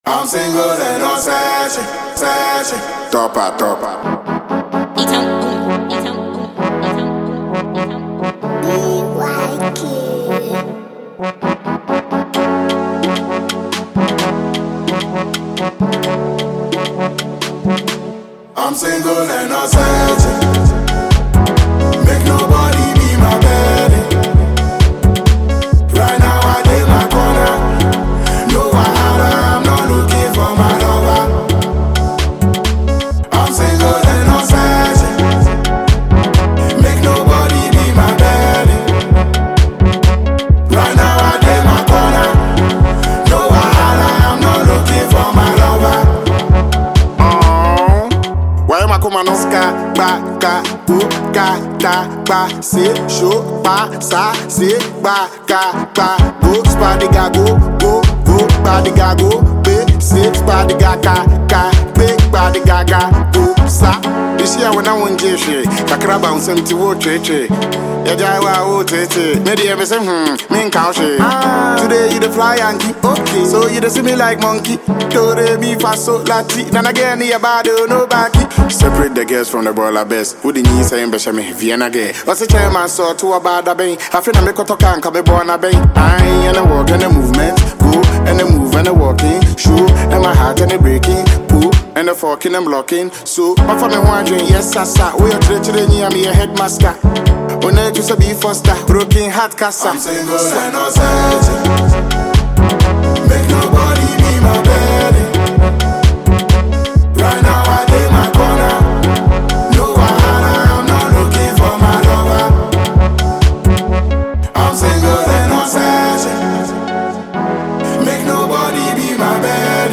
Ghanaian heavyweight hiphop musician